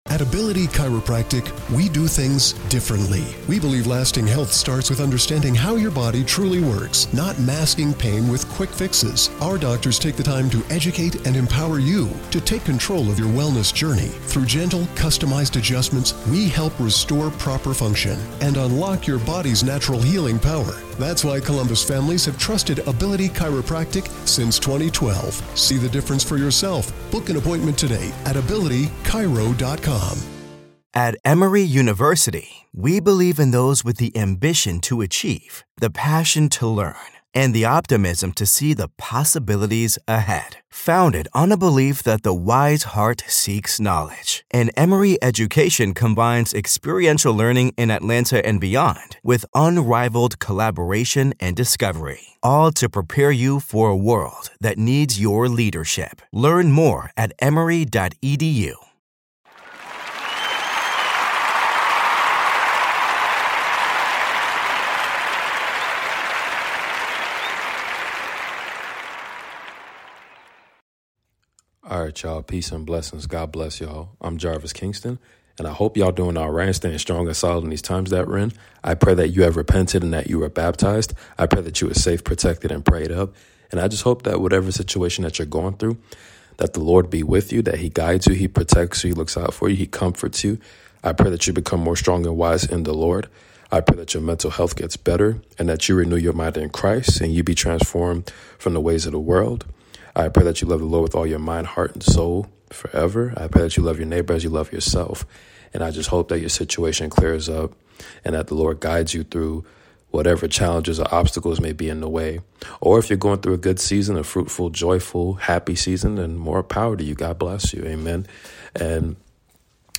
Continued reading of Genesis discussion of Isaac/Jacob !!!!!!